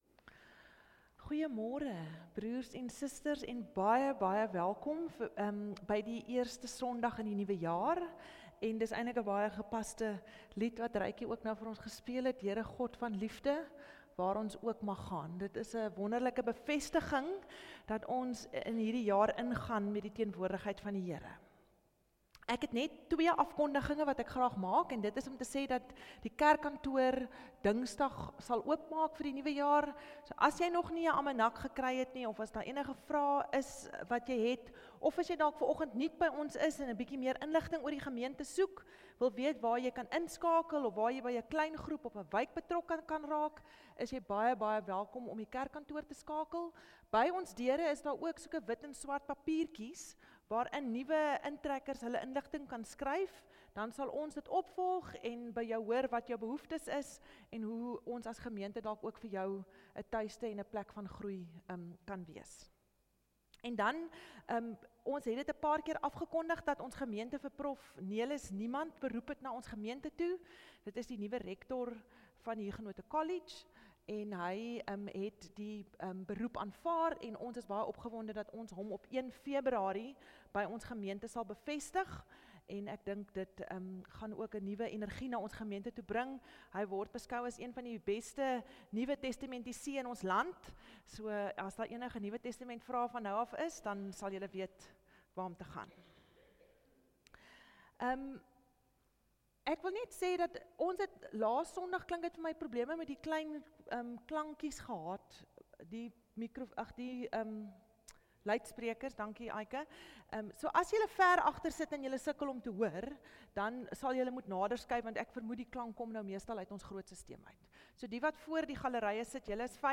Oggenddiens – 5 Januarie 2020